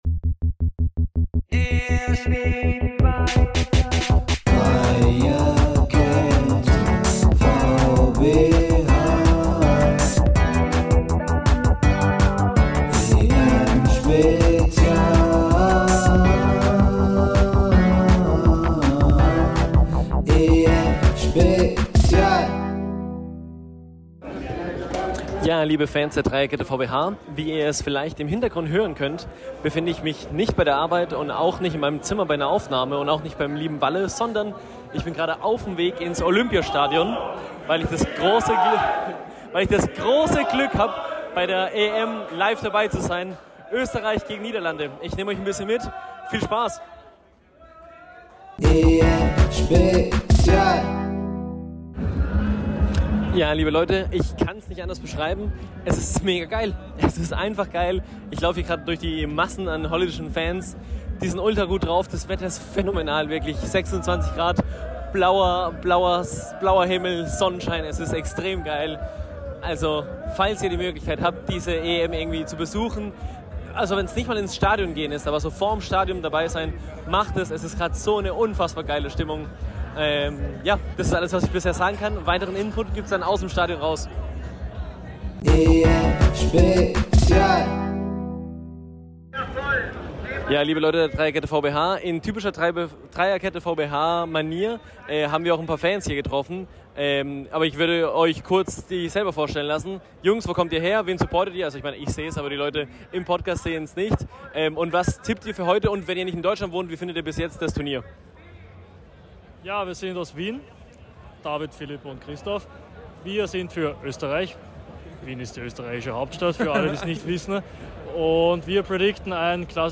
berichtet live aus dem Olympiastadion!